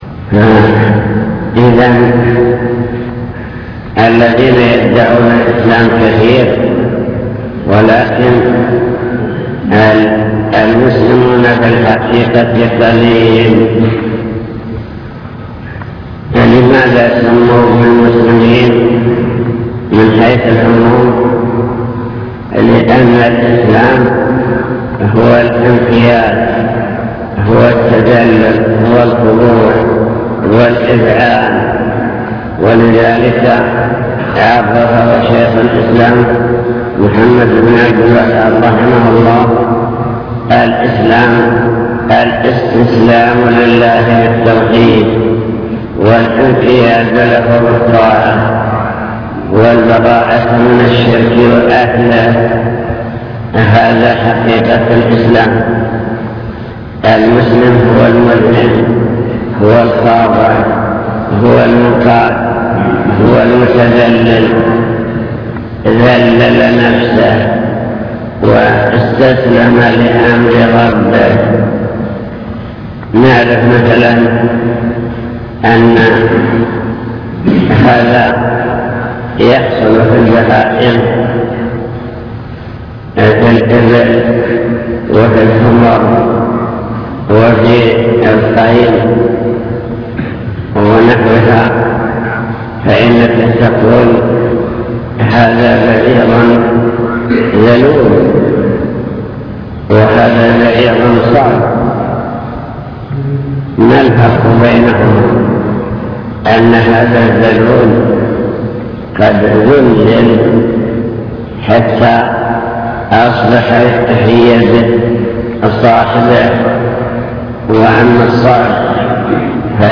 المكتبة الصوتية  تسجيلات - محاضرات ودروس  الإسلام والإيمان والإحسان